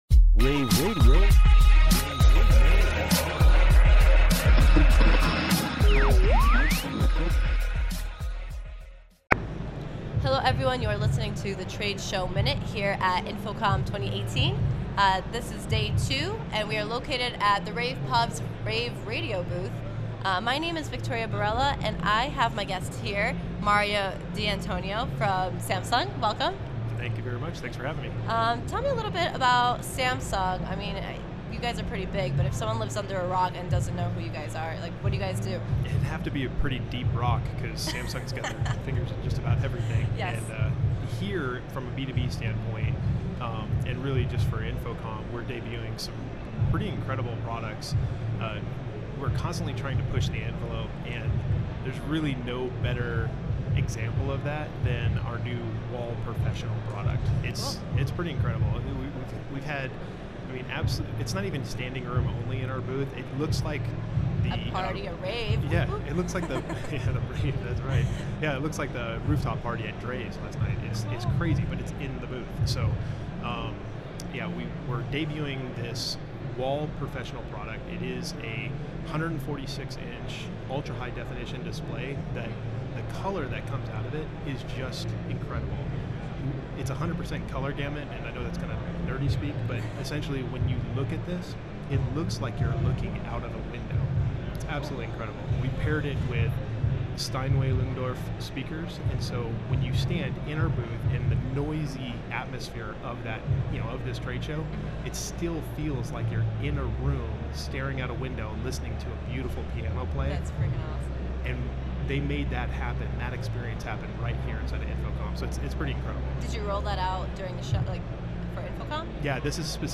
InfoComm Day2_showmin-231.mp3